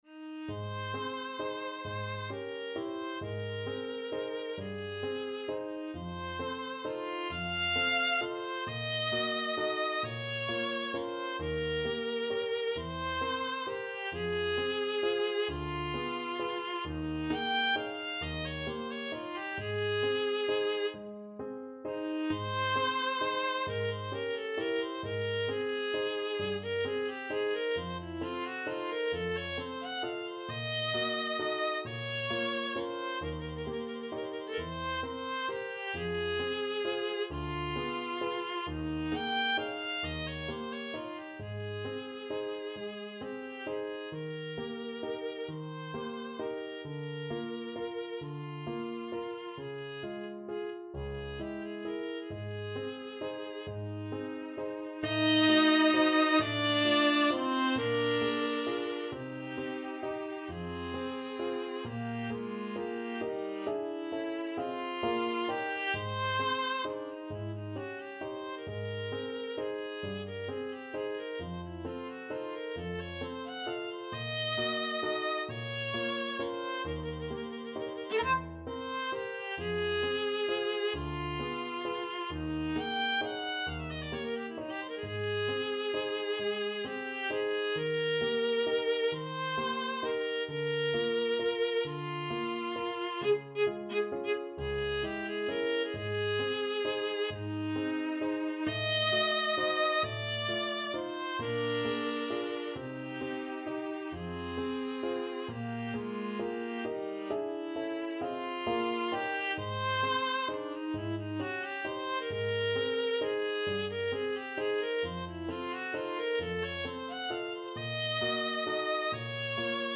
ViolaPiano
12/8 (View more 12/8 Music)
Andante . = 44
Classical (View more Classical Viola Music)
Nostalgic Music for Viola